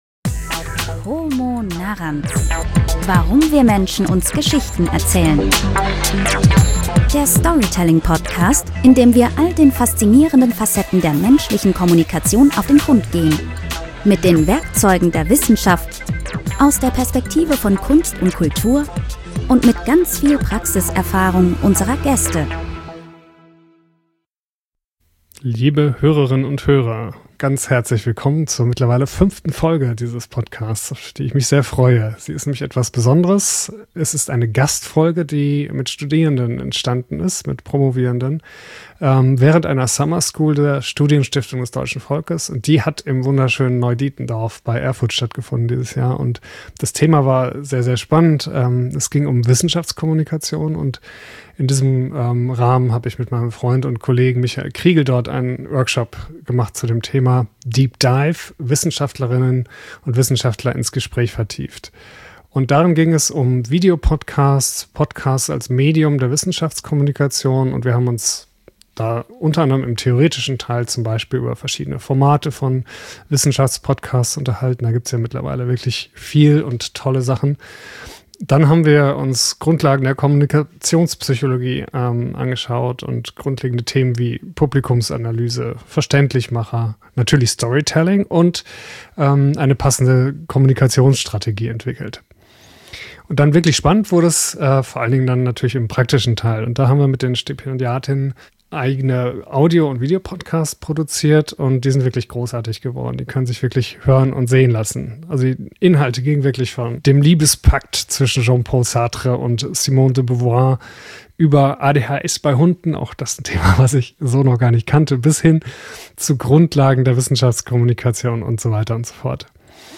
Wir waren im Museum, genauer in einem großartigen Ausstellungshaus. Der DASA Arbeitswelt Ausstellung in Dortmund. Dort haben wir intensiv über das Storytelling in Ausstellungen gesprochen.